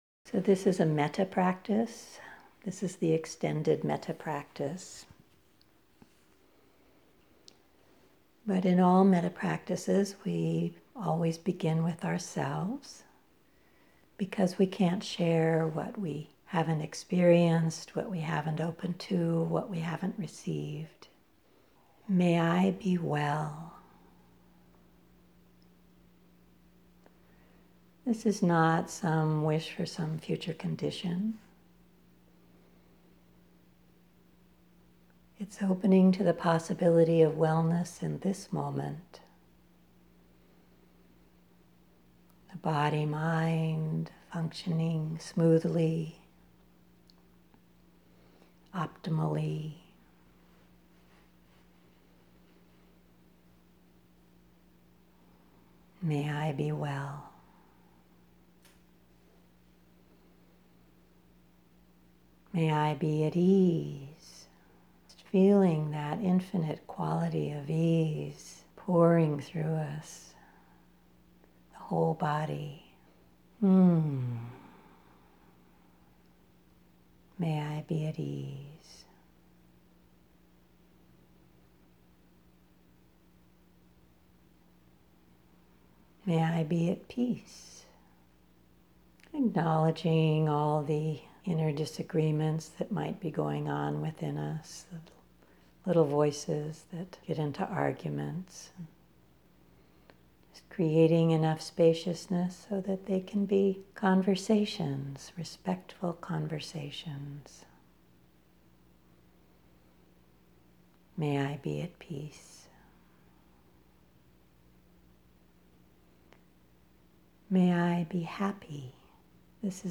Take a few minutes to meditate, and then give this metta practice a try.
EXTENDED METTA
metta-extended.mp3